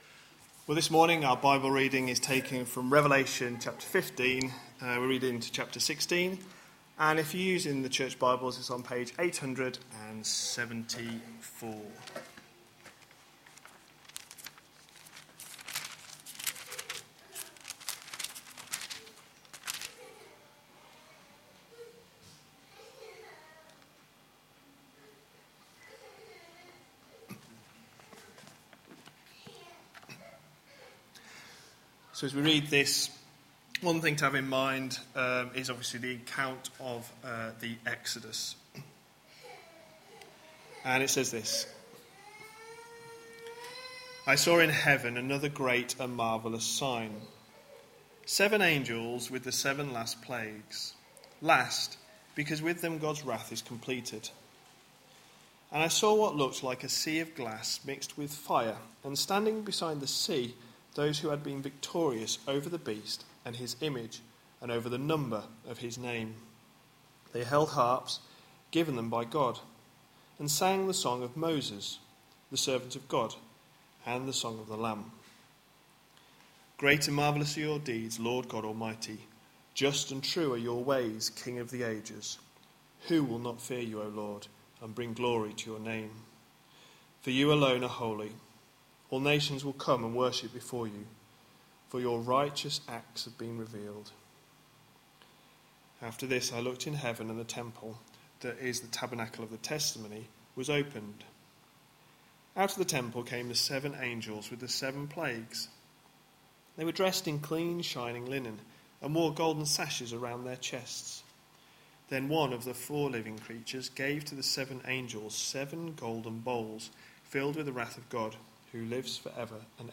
A sermon preached on 31st July, 2016, as part of our Revelation: A glimpse behind the curtain series.